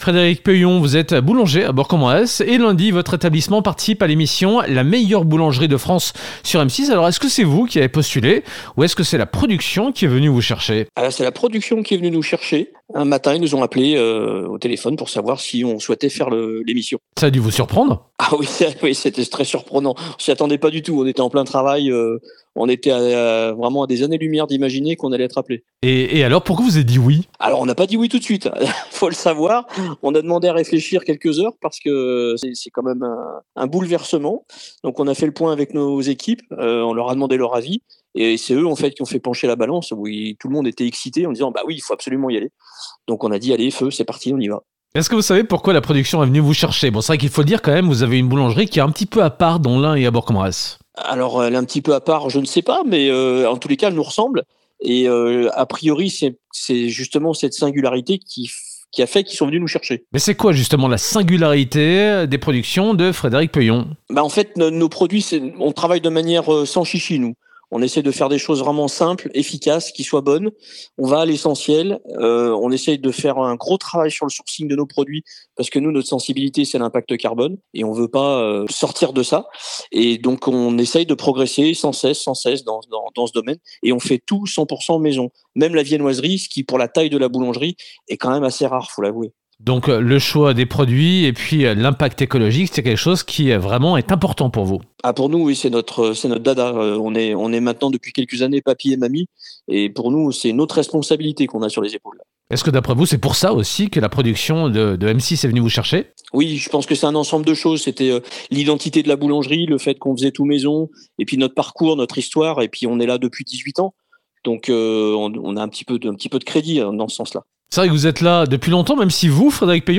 3. Interview de la Rédaction